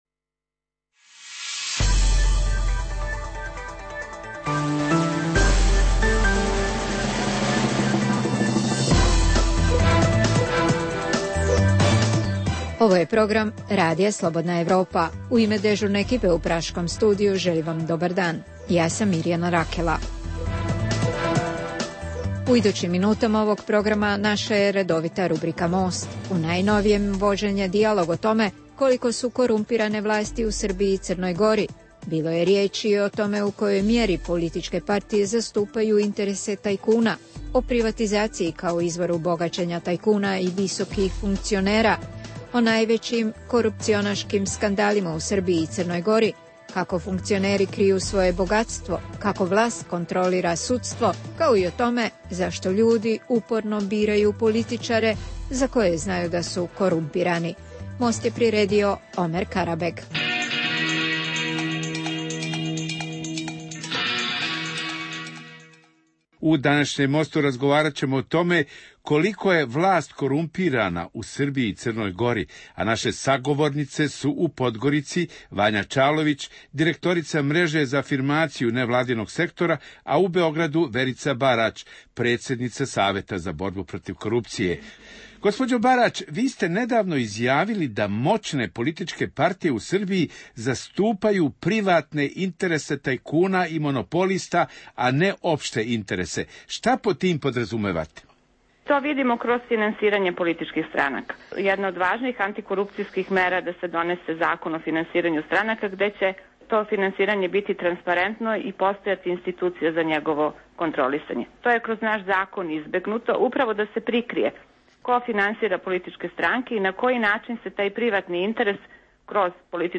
Ove nedjelje slušate "Radio most" u kojem je vođen dijalog o tome koliko je korumpirana vlast u Srbiji i Crnoj Gori.